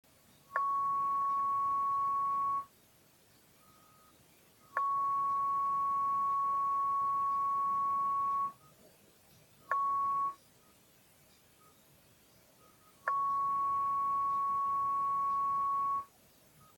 Получился интересный результат, если от источника он четко пикал (хоть и музыкально), то от длиннопроводной антенны он поет, если хватает напряжения на детекторе, при этом накопительный конденсатор тоже 1 мкФ.
А теперь треки. Записывал сотиком, около пьезика.